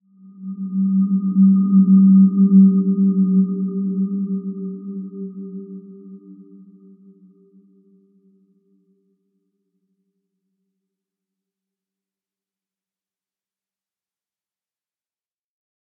Dreamy-Fifths-G3-mf.wav